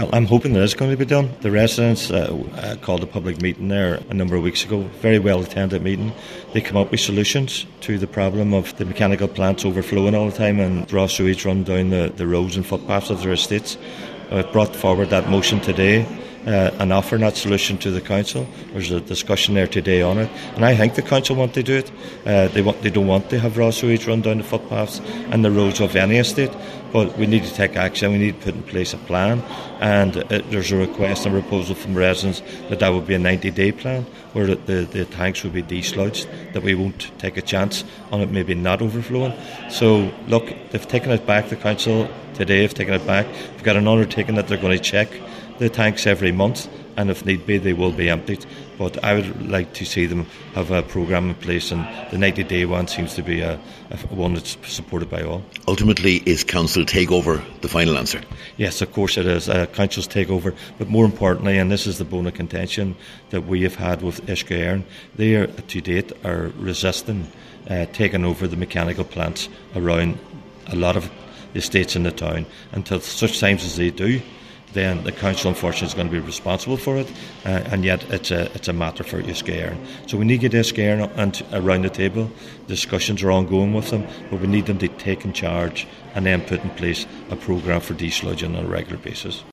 Cllr Gerry McMonagle raised the issue at Municipal District meeting this week – He says an agreement must be reached between the council and Uisce Eireann, but in the meantime, the tanks must be checked regularly and the necessary work done……….